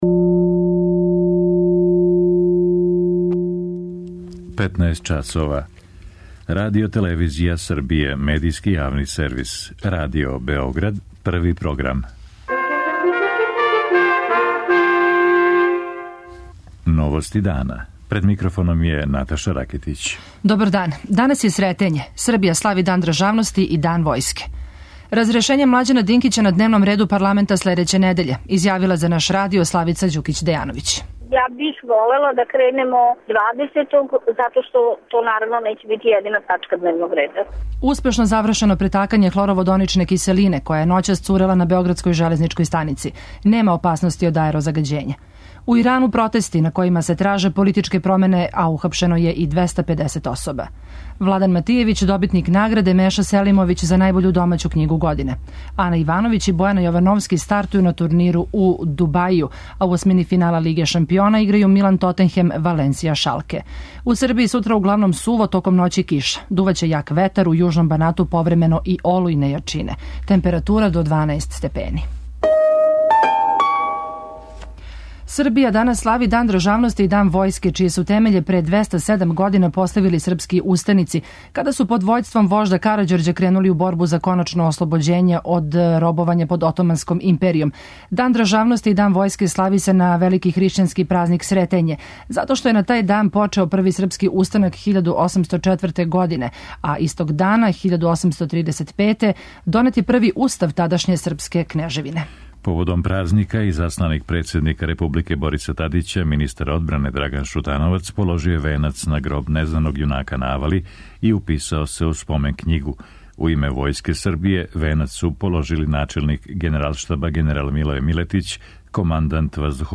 Србија данас слави Дан државности и Дан Војске. Наши репортери извештавају о обележавању овог празника из Тополе, Ваљева, Београда и Ниша.
преузми : 15.49 MB Новости дана Autor: Радио Београд 1 “Новости дана”, централна информативна емисија Првог програма Радио Београда емитује се од јесени 1958. године.